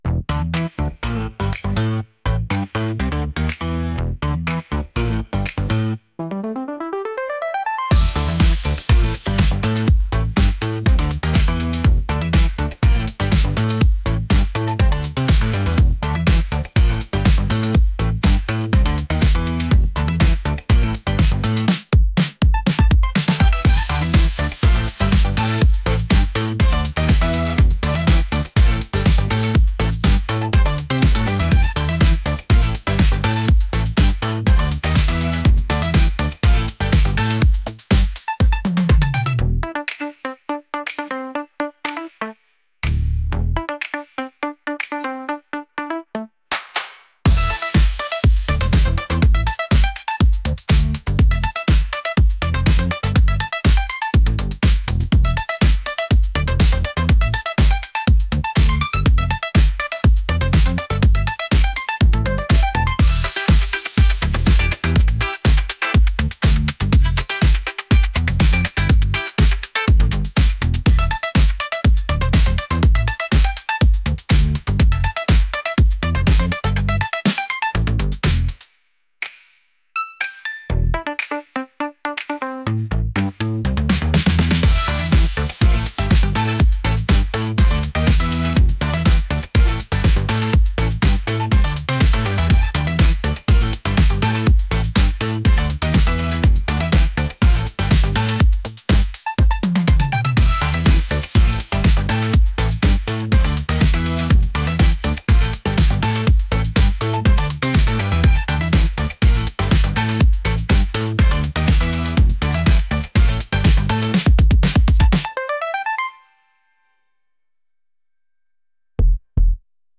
energetic | funky